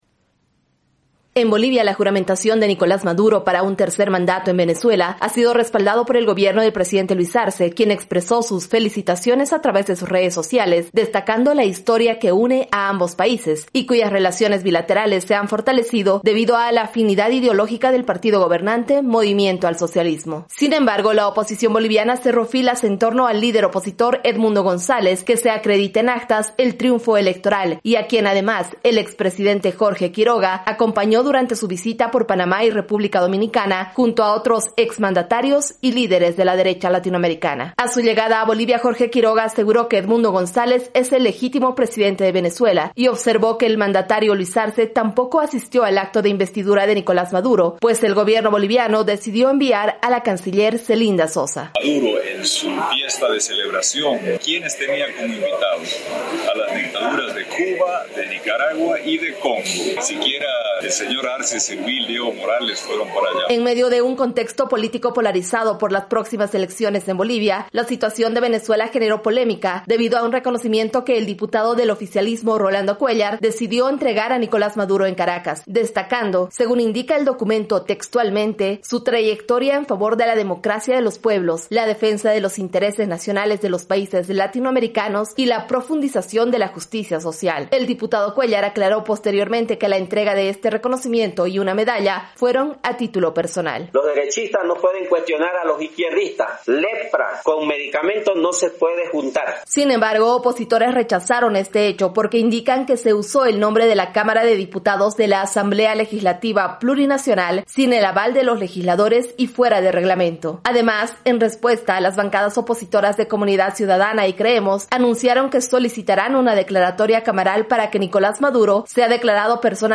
AudioNoticias